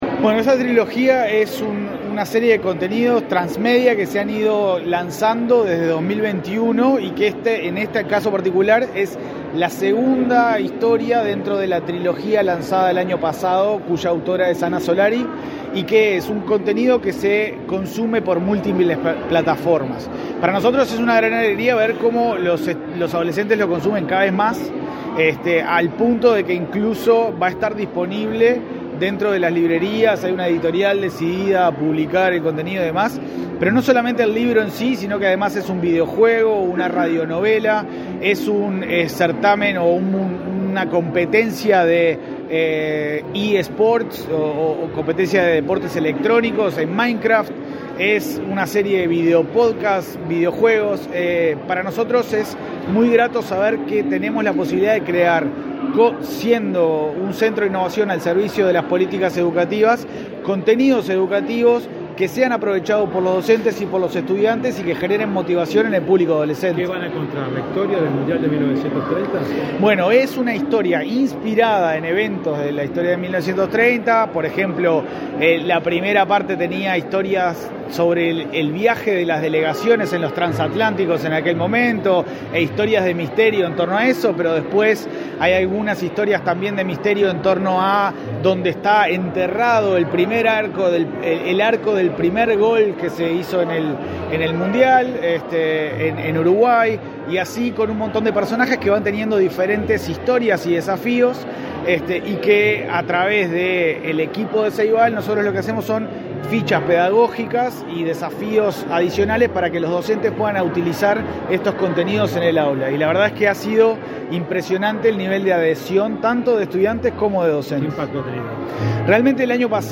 Declaraciones a la prensa del presidente de Ceibal, Leandro Folgar
Declaraciones a la prensa del presidente de Ceibal, Leandro Folgar 11/06/2024 Compartir Facebook X Copiar enlace WhatsApp LinkedIn Tras participar en el lanzamiento de la novela de Ceibal, “1930, el origen”, este 11 de junio, el presidente de Ceibal, Leandro Folgar, realizó declaraciones a la prensa.